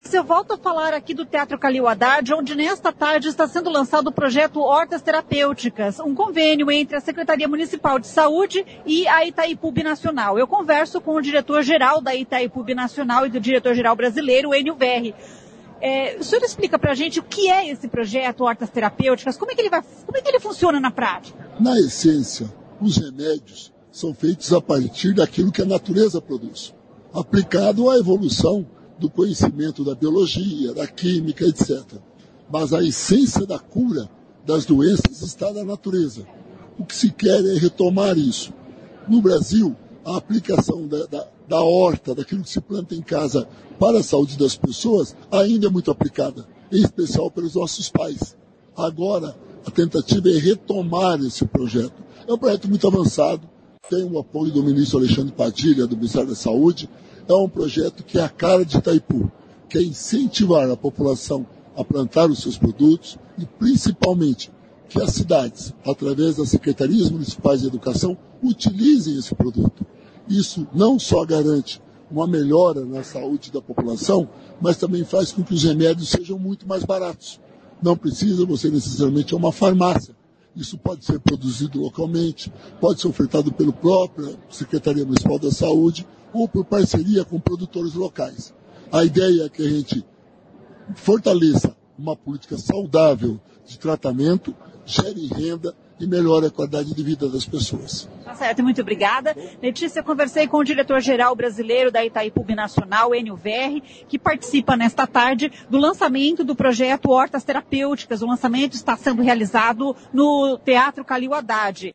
O presidente da Itaipu Binacional Ênio Verri explica como é o projeto Hortas Terapêuticas.